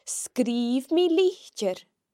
The slender sgr can be heard in sgrìobh (wrote):